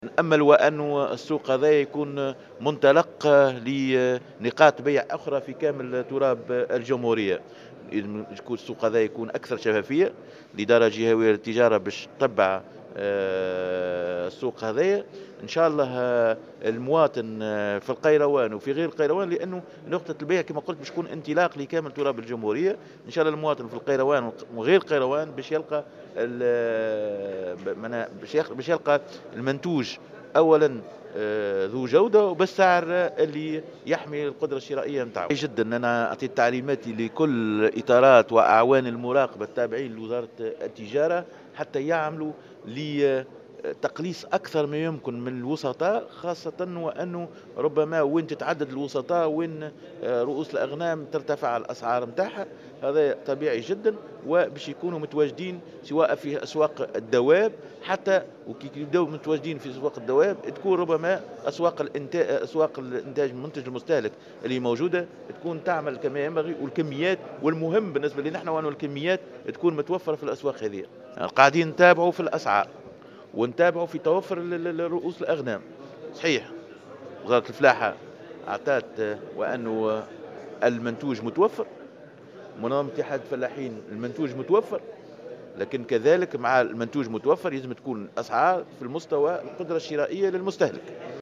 تم اليوم السبت افتتاح أول سوق لبيع أضاحي العيد من المنتج إلى المستهلك بولاية القيروان على أن يتم لاحقا فتح عدّة نقاط بيع أخرى بكافة ولايات الجمهورية،وفق ما أكده وزير التجارة رضا الأحول في تصريح لمراسل "الجوهرة أف أم" .